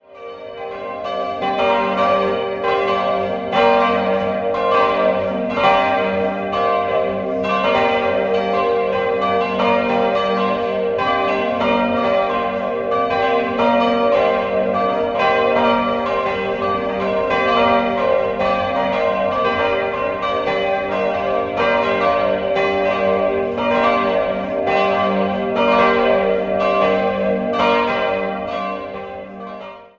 4-stimmiges Geläute: g'-b'-c''-d'' Alle Glocken wurden 1978 von Rudolf Perner in Passau gegossen.